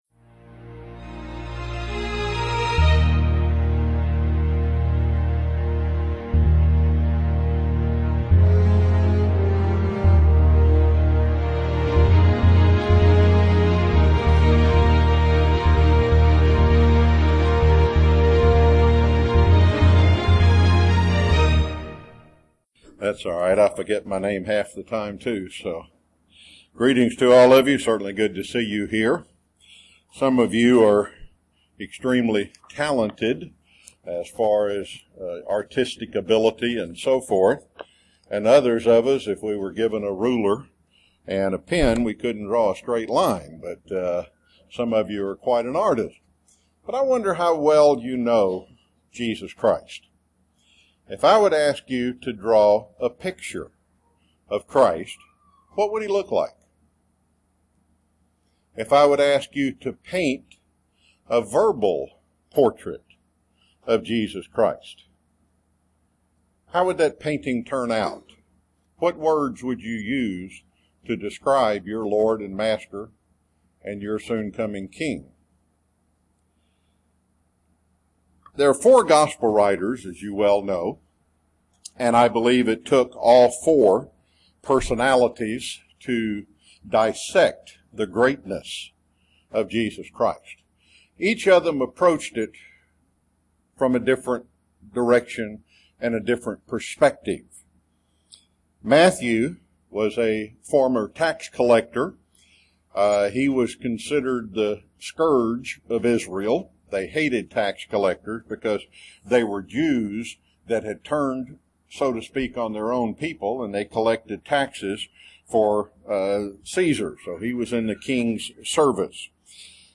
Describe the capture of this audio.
Given in Chattanooga, TN